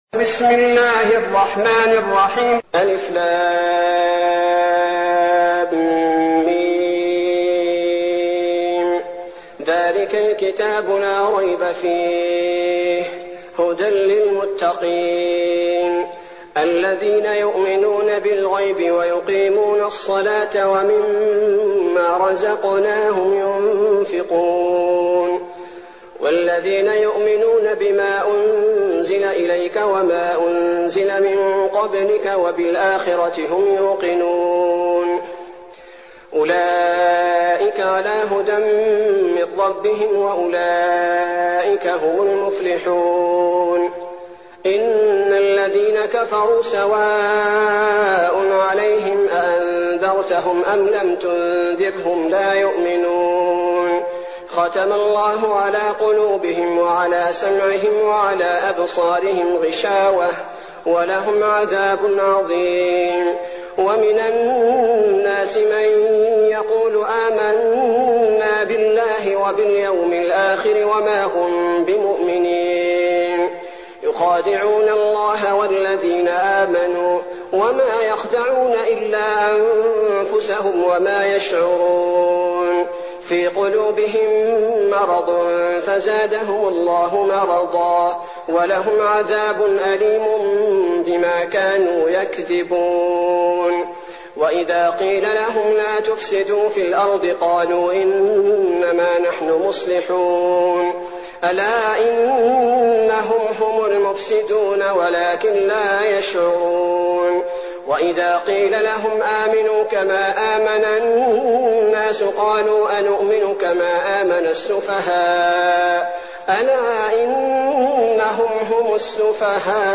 المكان: المسجد النبوي الشيخ: فضيلة الشيخ عبدالباري الثبيتي فضيلة الشيخ عبدالباري الثبيتي البقرة